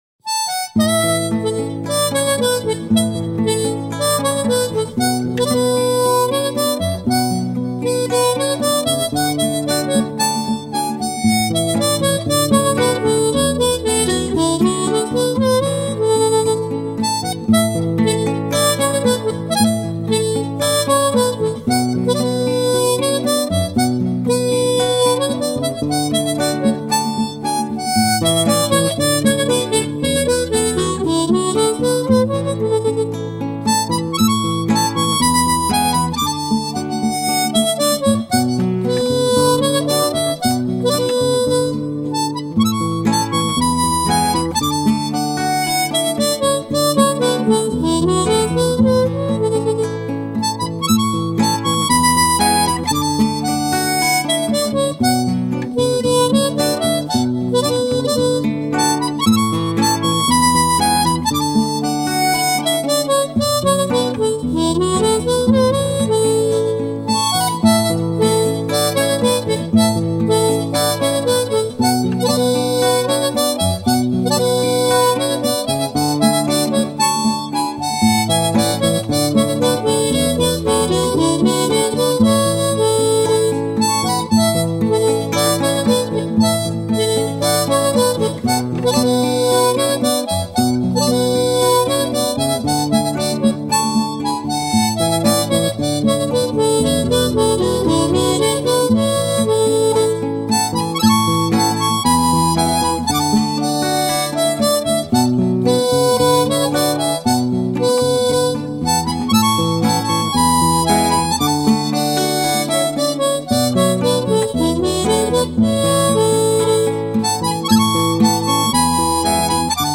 First Position Tunes
This bend is a whole tone, written as 3D", and is deeper than the 3D bend commonly used in blues.
To practice the 3D" note, learn